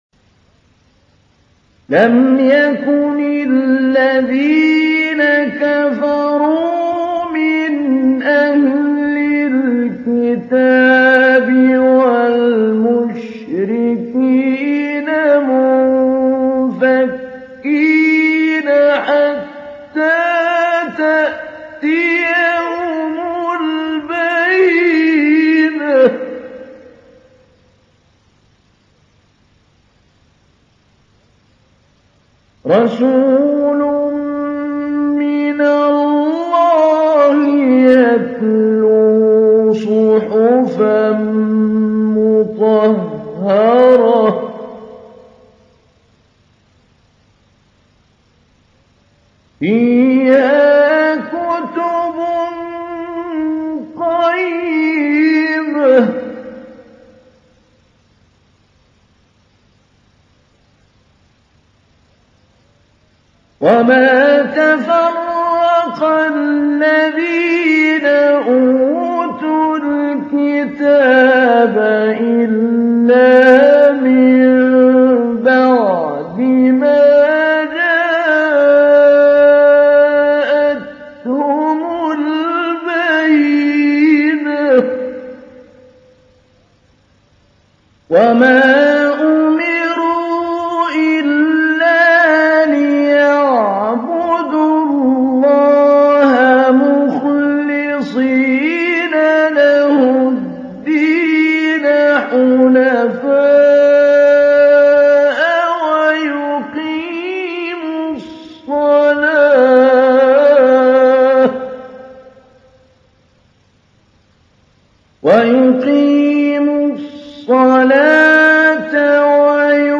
تحميل : 98. سورة البينة / القارئ محمود علي البنا / القرآن الكريم / موقع يا حسين